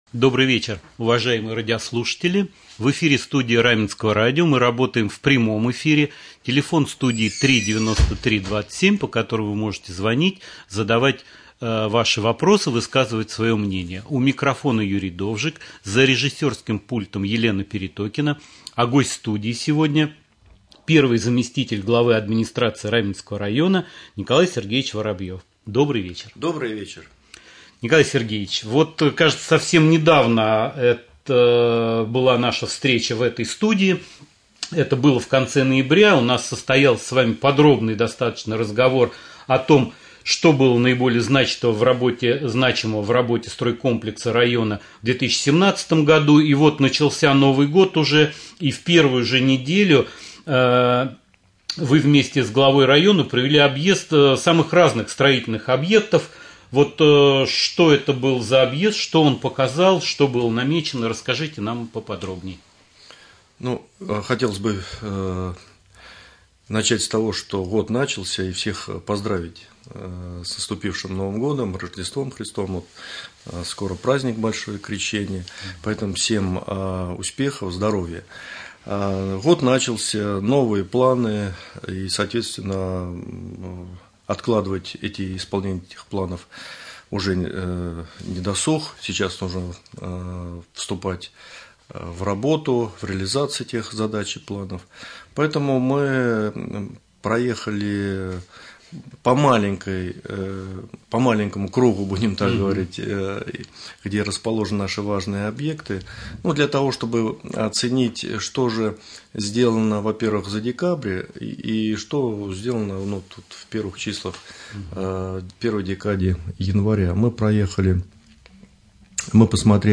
Прямой эфир. Гость студии первый заместитель Главы Раменского муниципального района Николай Сергеевич Воробьев.